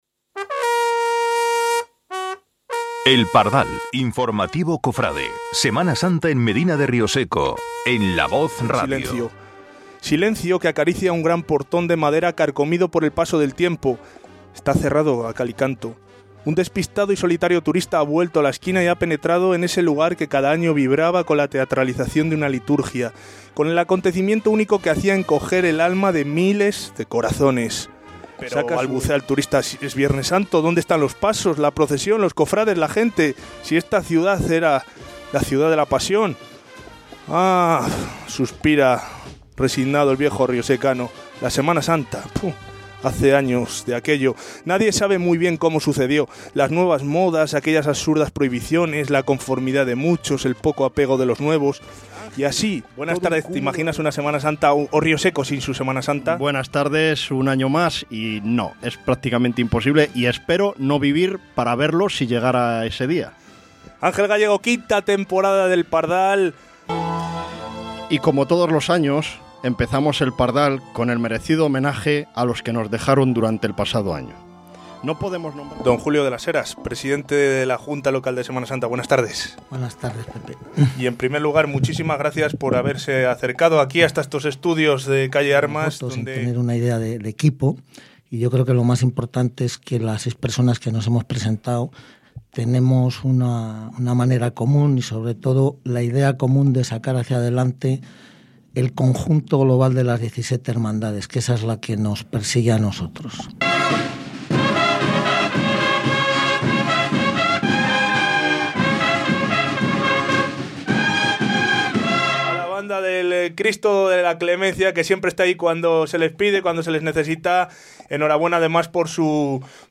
Pero si eres de los que no pueden esperar más, La Voz de Rioseco te ofrece un amplio resumen de más de hora y media con los mejores momentos de la quinta temporada de El Pardal (año 2016). Por los micrófonos de La Voz pasaron más de 200 voces distintas en seis intensos programas con más de diez horas de informaciones, música, entrevistas, sonrisas, lágrimas y muchas sorpresas.